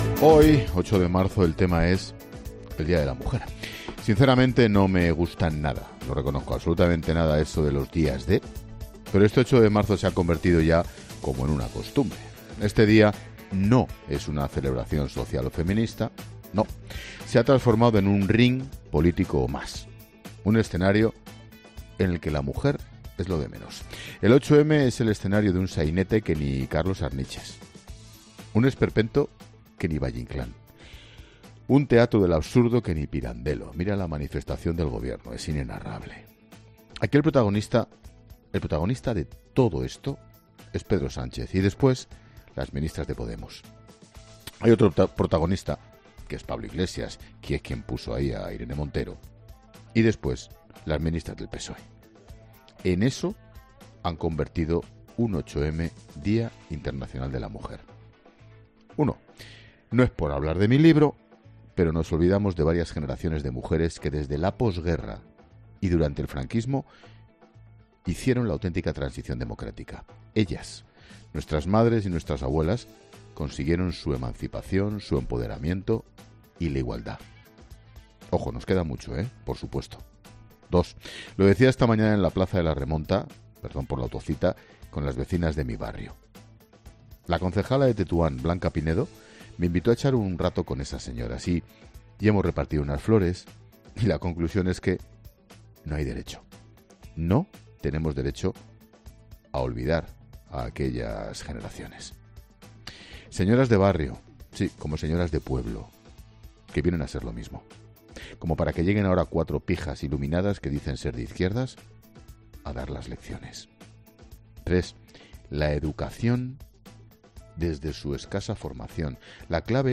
El director de La Linterna hace su particular análisis del Día de la Mujer y reivindica otros perfiles olvidados en la historia del feminismo